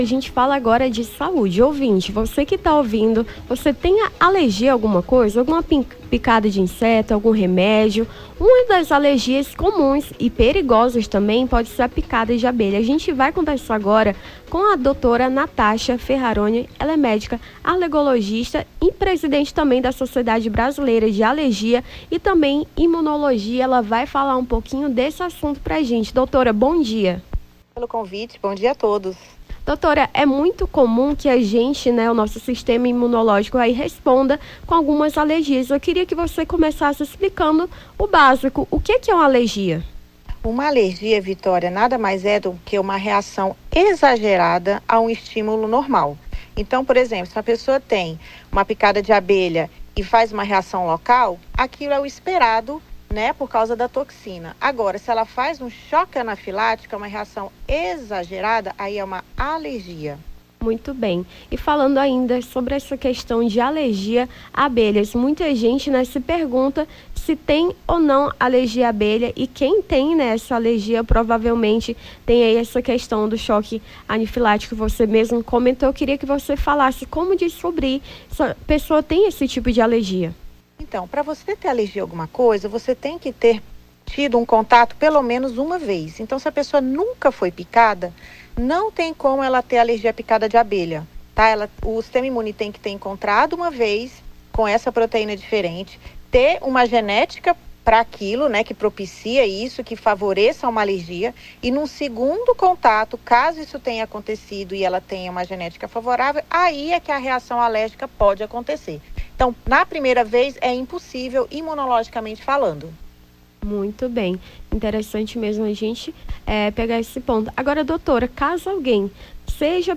Nome do Artista - CENSURA - ENTREVISTA PICADA ABELHA (29-05-24).mp3